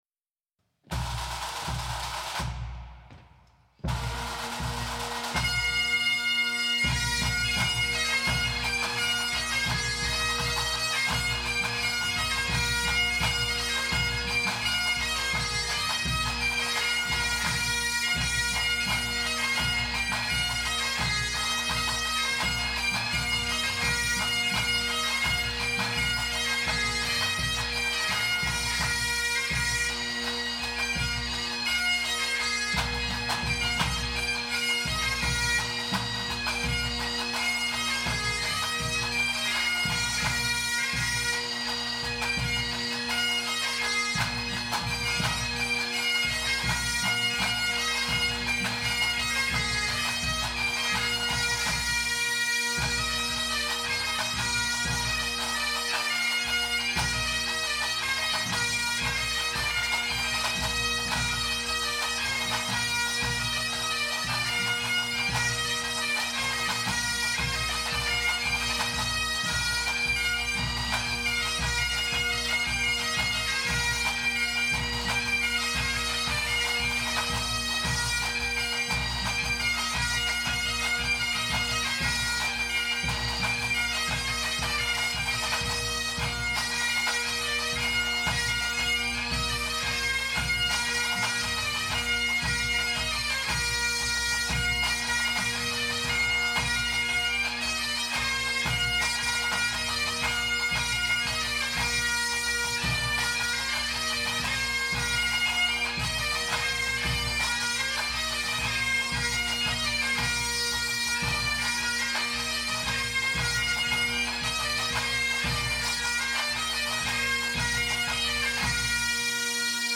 I hooked up with a small group of pipers and drummers called Tucson and District Pipe Band.
With careful management, it is possible to achieve a true bagpipe sound!
The audio clip below is the 2019 medley. There were blowing and unison issues however I was pleased with our progress.